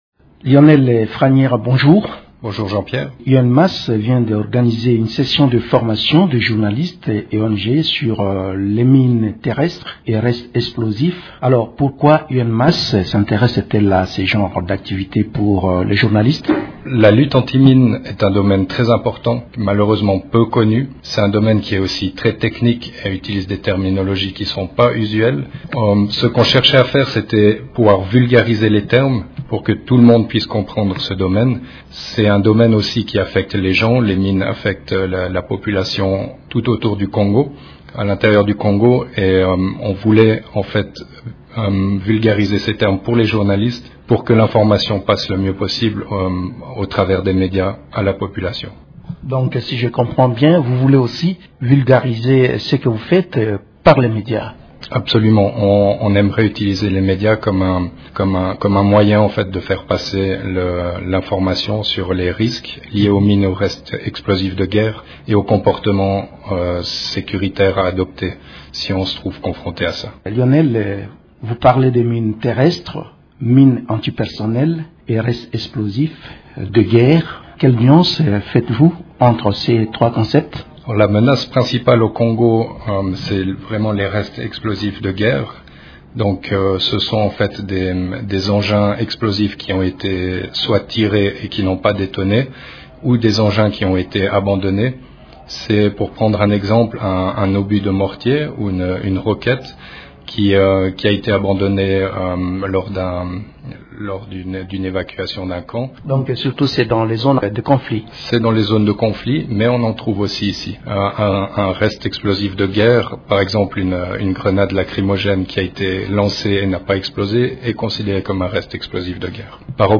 Dans cet entretien